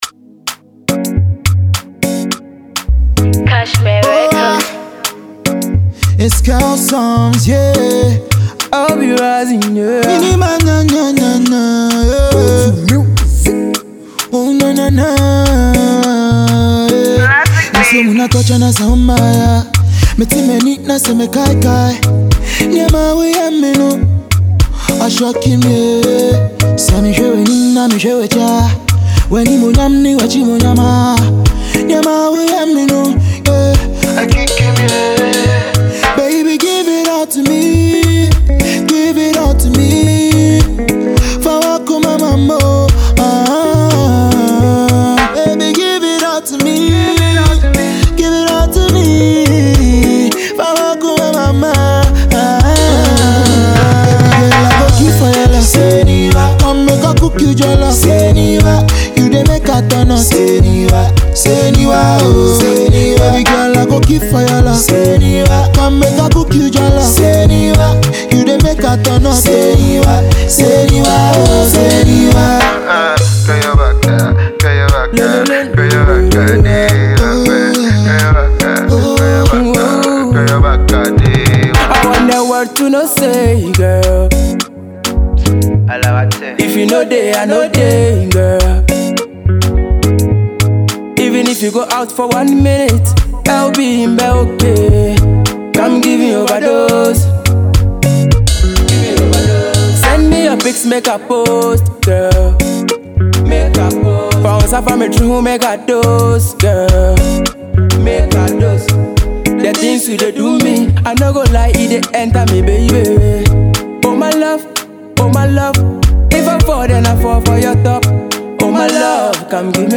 prolific singer